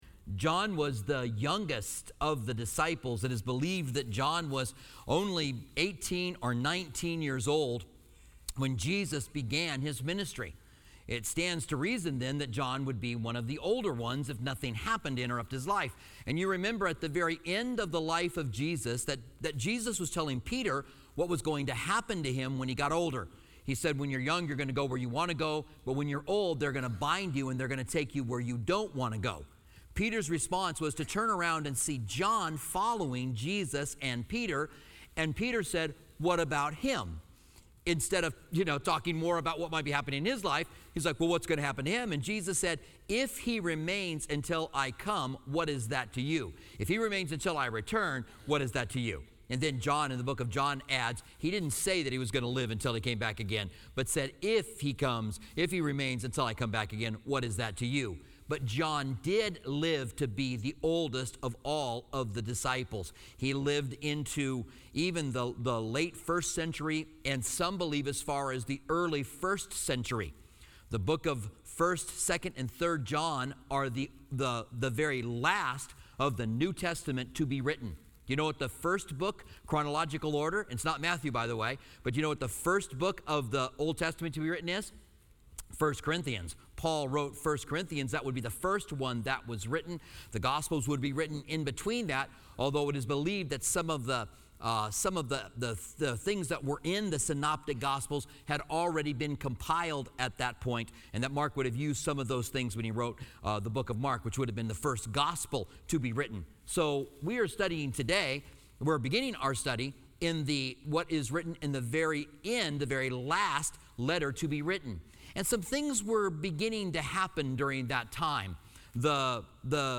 Commentary on 1 John